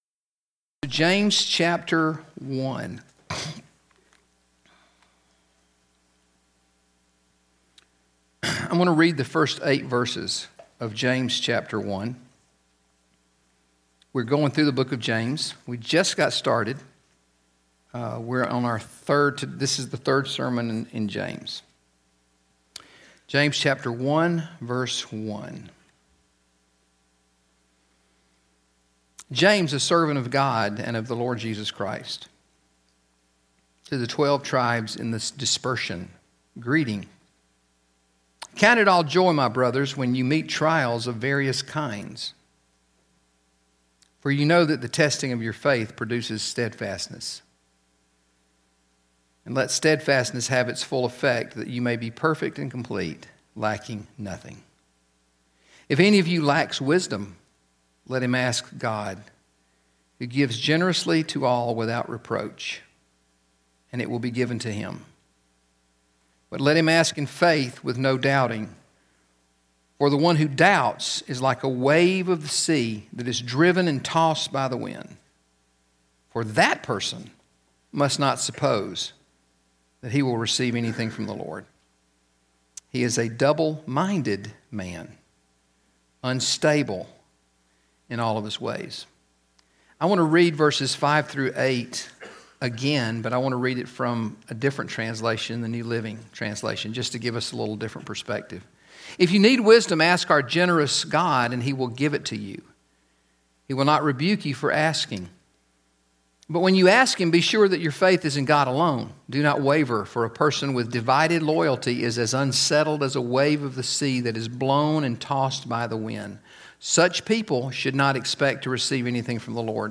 GCC Sermons | Gwinnett Community Church Sermons